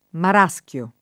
[ mar #S k L o ]